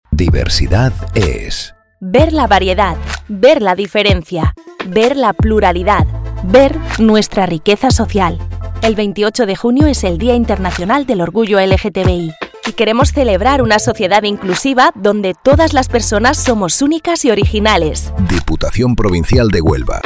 Cuña de radio